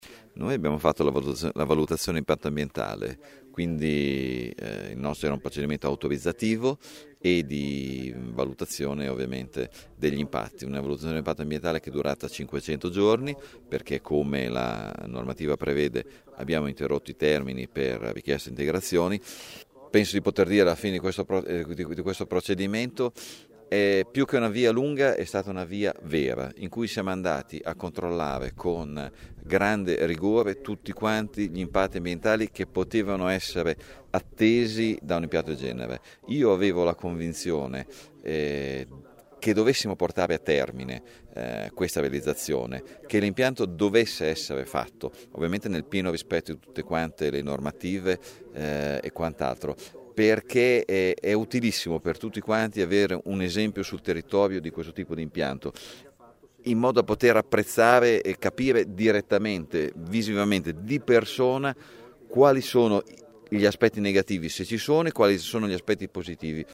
L’assessore all’ambiente della Provincia di Bologna Emanuele Burgin